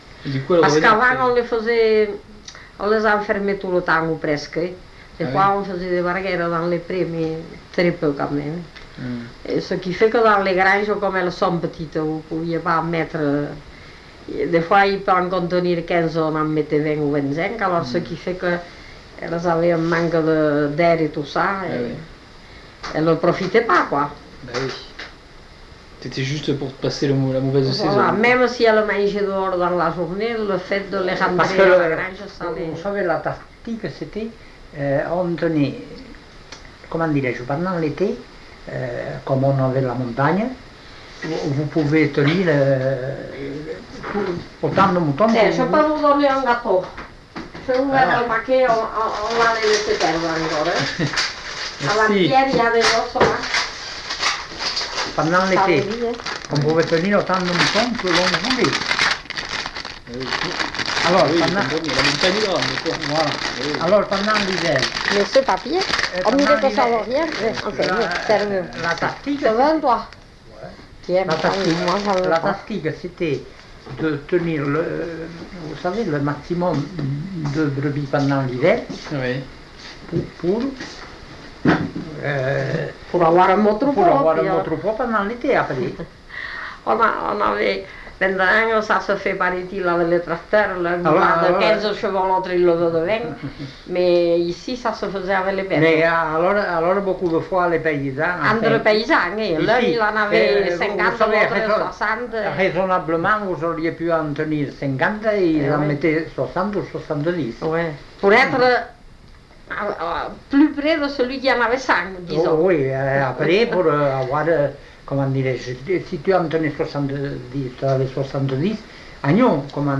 Aire culturelle : Couserans
Lieu : Eylie (lieu-dit)
Genre : témoignage thématique